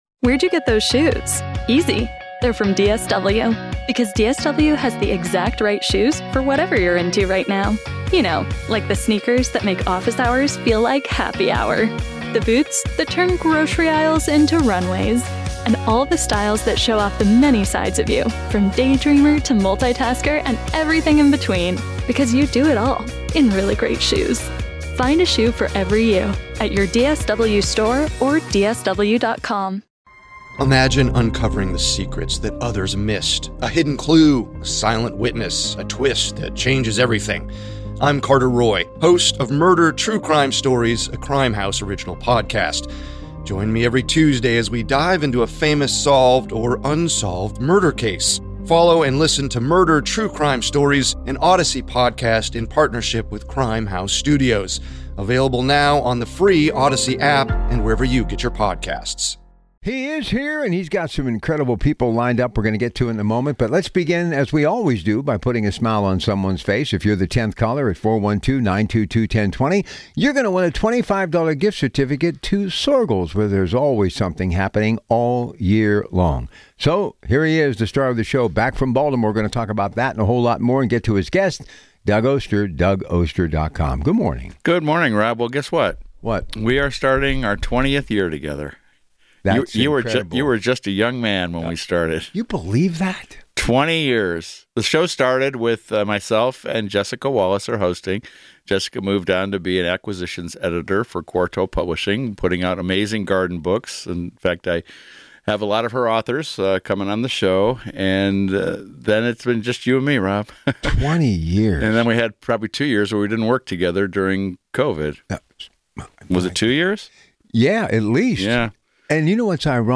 The Organic Gardener airs live Sundays at 7:00 AM on KDKA Radio.